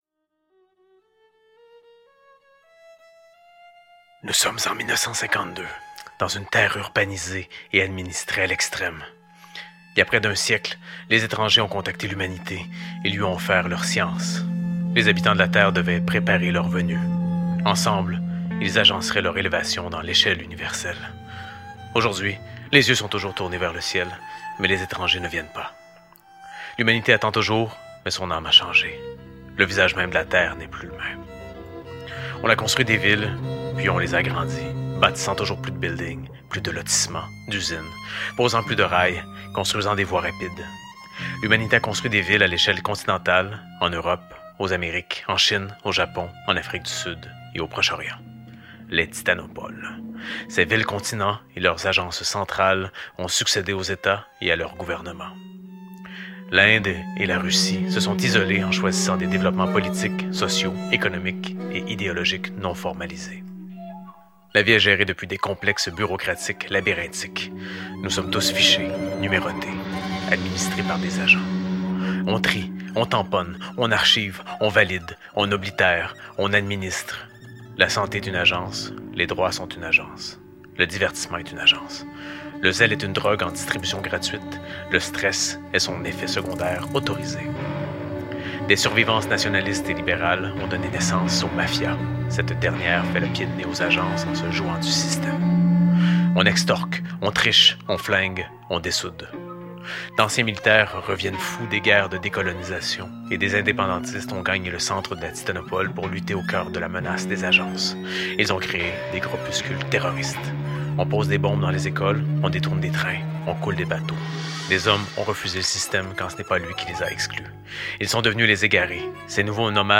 (Radiothéâtre) RétroFutur – L’univers
Extrait de l'épisode 8 "Babylone" de "Aube'" notre émission de radio diffusée à Montréal sur les ondes de "CIBL 101,5 FM" mercredi à 13h, à Québec sur les ondes de CKIA 88,3 FM le dimanche 13 h et à Sherbrooke sur les ondes de CFLX 95,5 FM dimanche à 15 h. Disponible en balado...